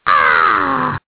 One of Toad's voice clips in Mario Kart DS